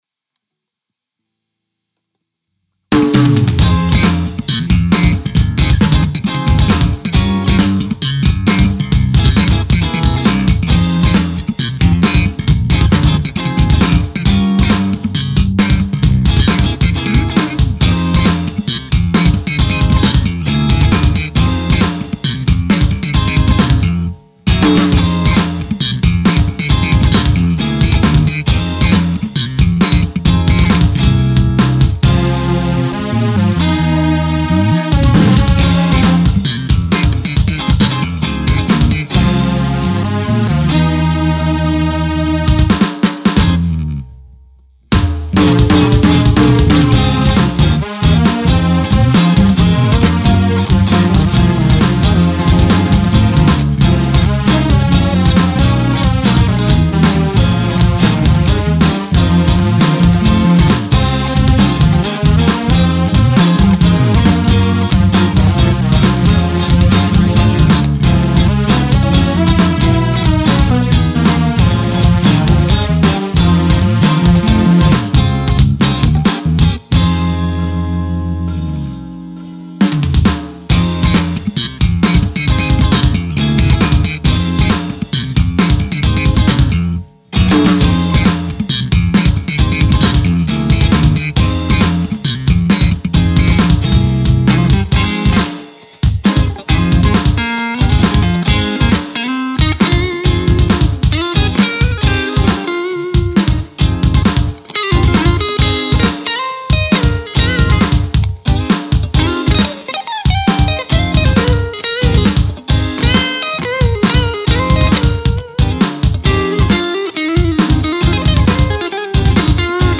楽曲のジャンルはファンク・フュージョンっぽいポップスがメインで男ボーカルの歌モノです。
歌録りがまだなのですが雰囲気だけでも感じてもらえる様デモ音源を送付しますのでお聞き頂けましたら幸いです。
ポップス, ロック, ジャズ/フュージョン